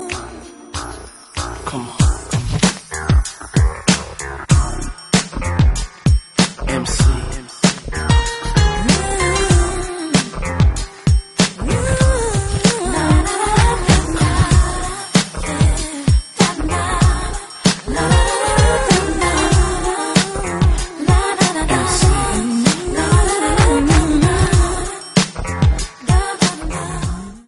une boucle de basse entêtante
quelques notes de piano bien posées